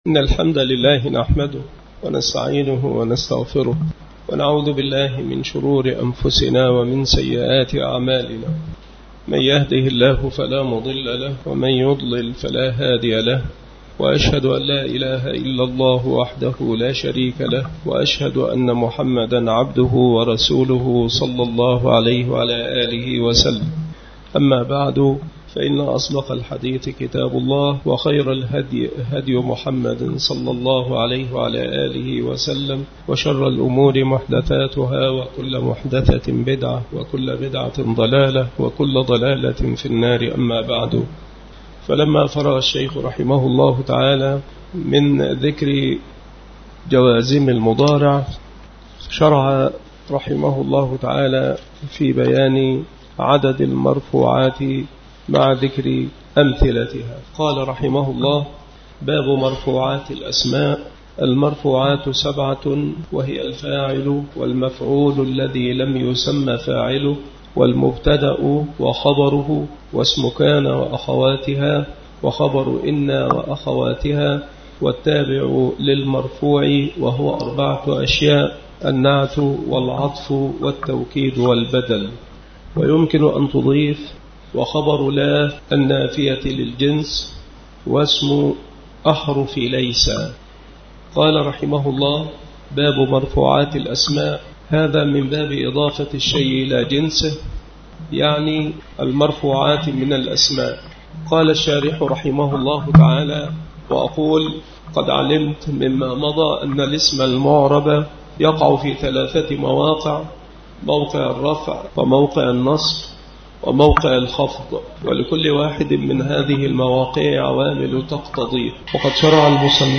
مكان إلقاء هذه المحاضرة بالمسجد الشرقي بسبك الأحد - أشمون - محافظة المنوفية - مصر عناصر المحاضرة : باب مرفوعات الأسماء. عدد المرفوعات وأمثلتها. باب الفاعل.